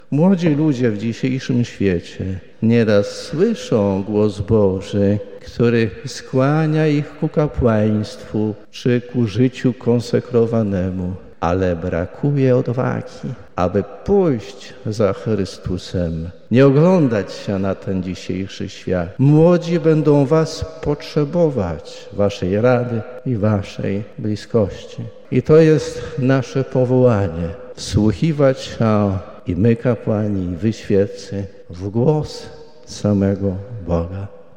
Wiara: Msza Święta Krzyżma w Łomżyńskiej Katedrze